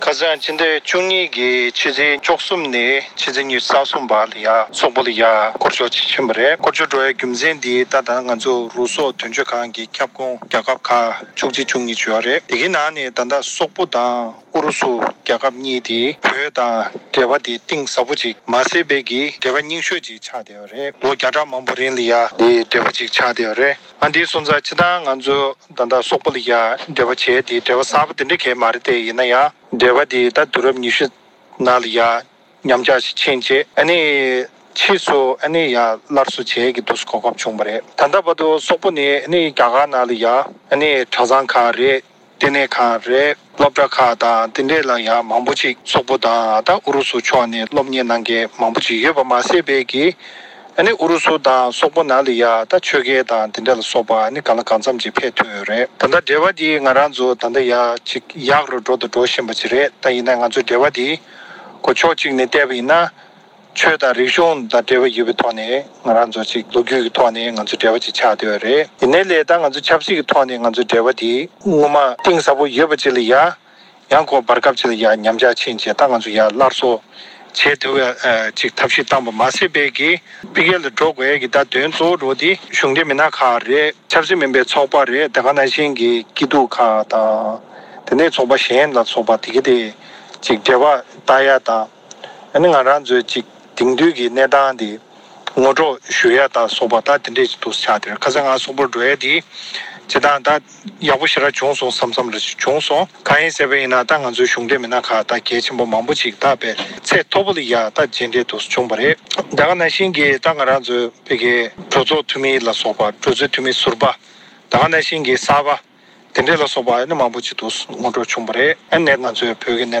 ཞིབ་ཕྲ་འདི་ག་རླུང་འཕྲིན་ཁང་ནས་ཏེ་ལོ་རིན་པོ་ཆེ་མཆོག་ལ་བཀའ་འདྲི་ཞུ་སྐབས་འགྲེལ་བརྗོད་འདི་ལྟ་གནང་གི་འདུག
སྒྲ་ལྡན་གསར་འགྱུར།